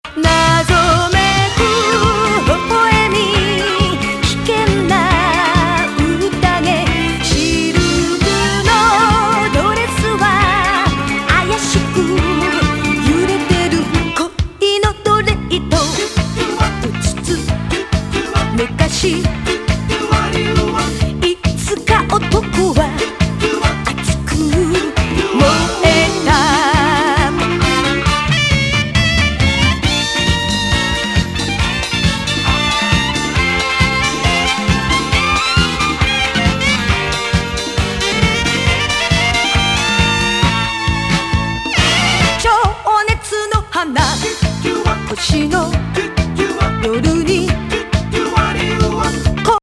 邦ディスコ・カバー!